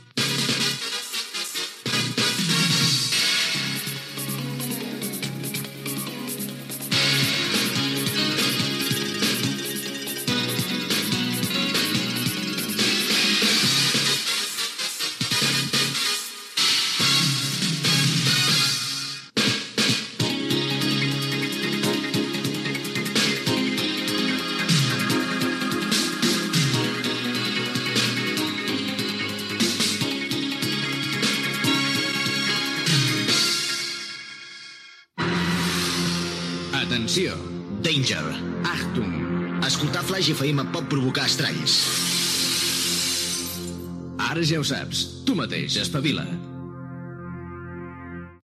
Primer dia de l'emissió regular.